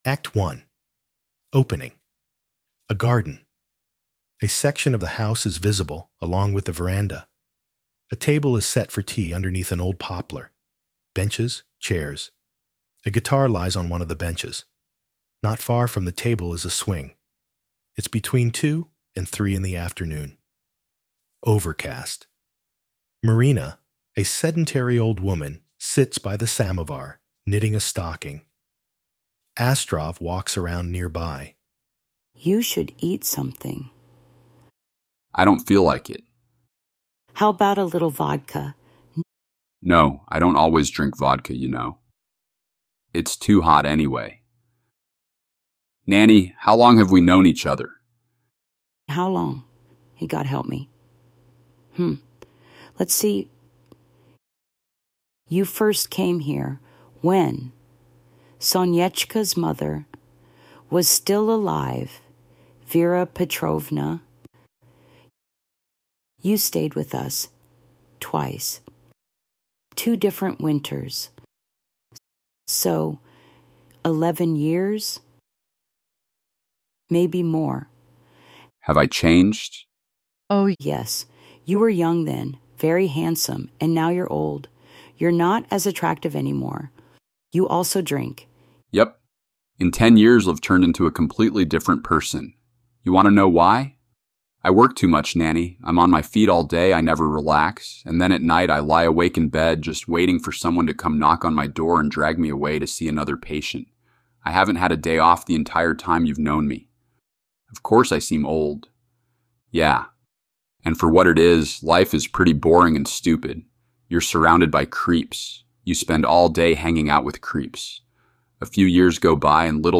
Narration (elevenlabs)